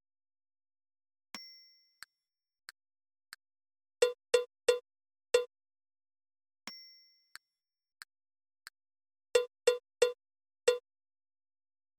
「リズムカード」正解のリズム音源
※リズム音源のテンポは♩＝90です。Moderato/モデラート(♩＝76～96)ほどのテンポとなります。
※はじめにカウントが流れます。
※リズムは2回流れます。